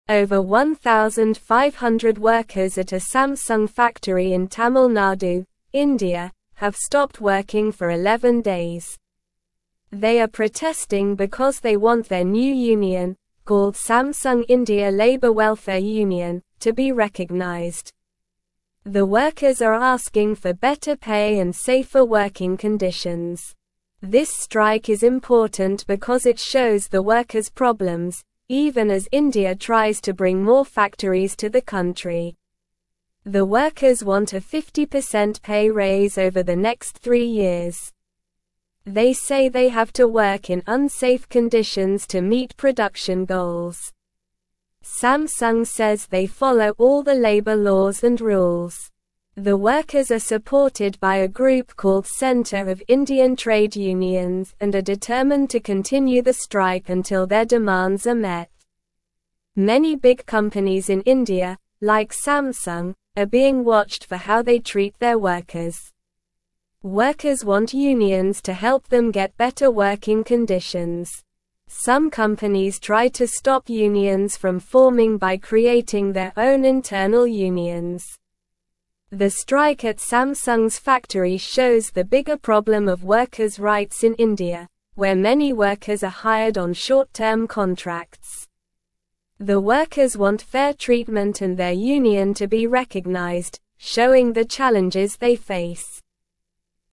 Slow
English-Newsroom-Lower-Intermediate-SLOW-Reading-Workers-at-Samsung-in-India-stop-working-together.mp3